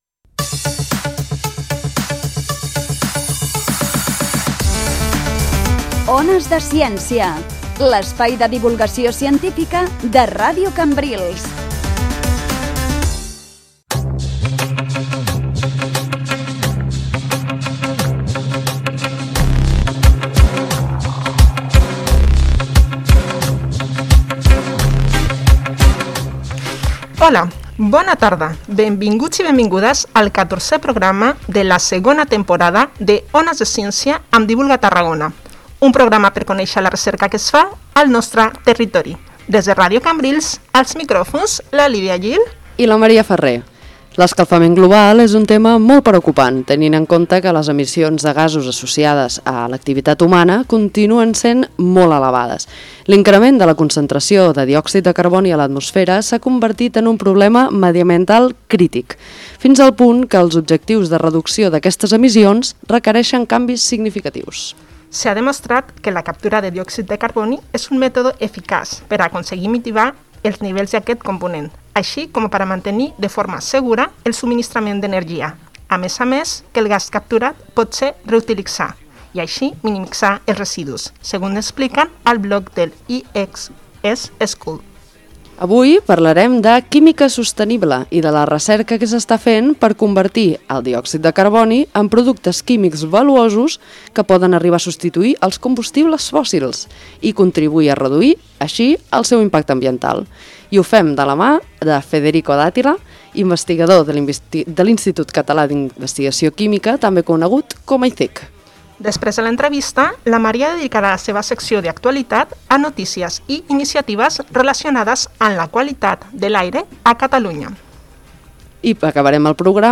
Each chapter starts with a live conversation with a local researcher, with both professional and personal topics discussed.